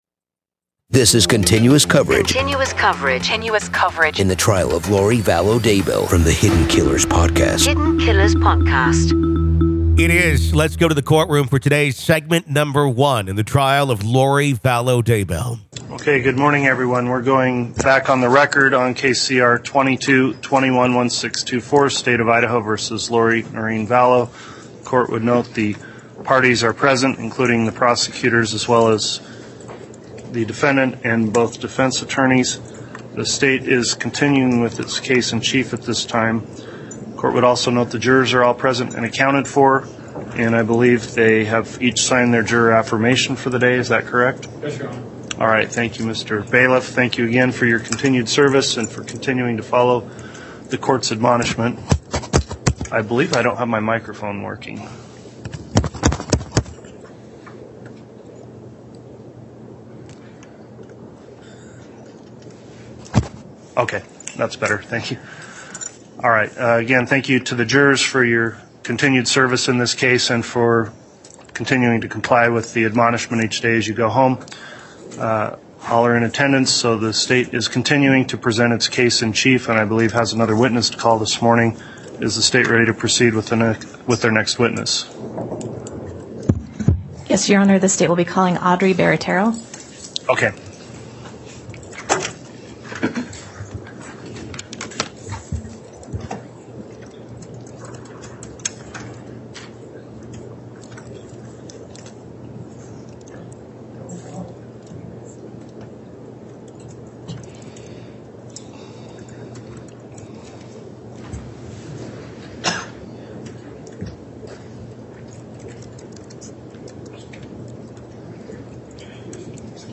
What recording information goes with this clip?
With raw courtroom audio, and interviews from experts and insiders, we analyze the evidence and explore the strange religious beliefs that may have played a role in this tragic case.